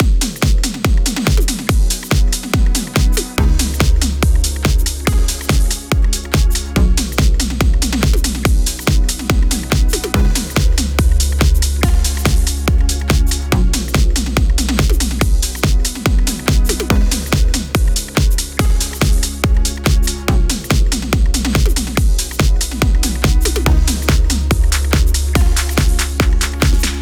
Gb Major
Excited (Red)
Pop Perfect
Bit Cracks
Classic Warm
Odd Harmonies